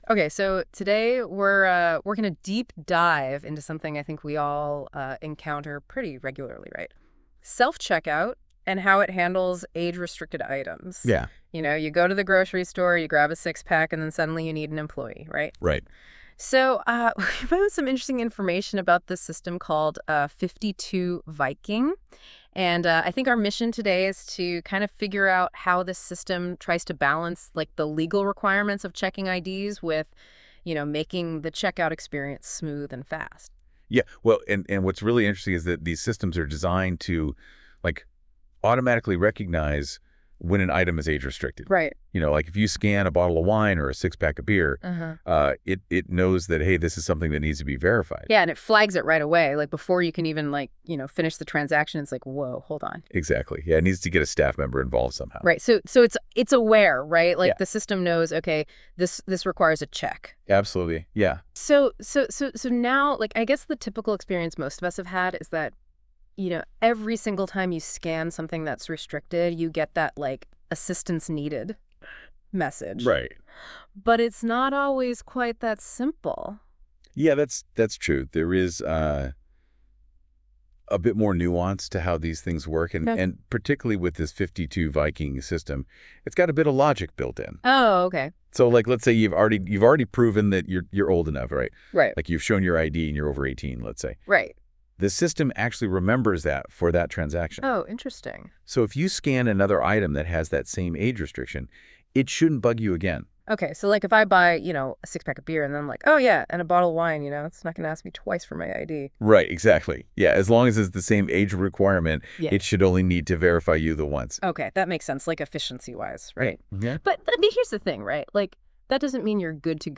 Listen to conversational-style